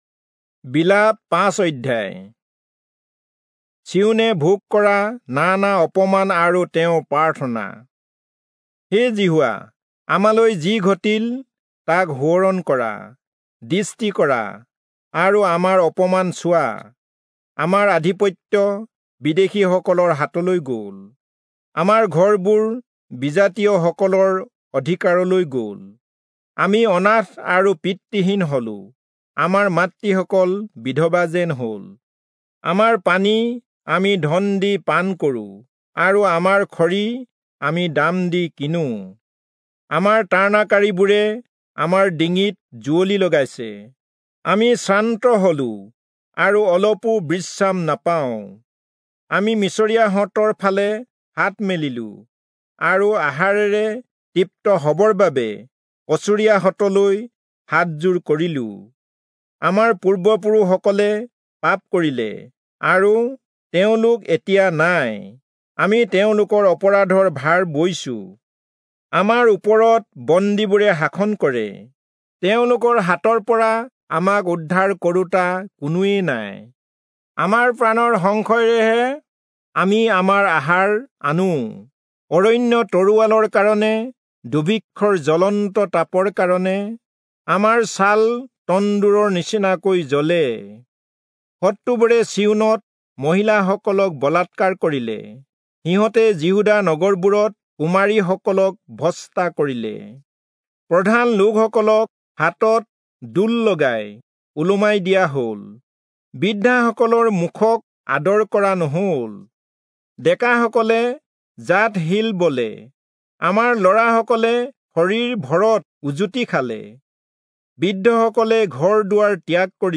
Assamese Audio Bible - Lamentations 4 in Asv bible version